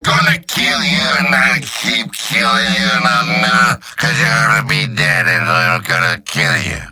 Robot-filtered lines from MvM. This is an audio clip from the game Team Fortress 2 .
Demoman_mvm_gibberish09.mp3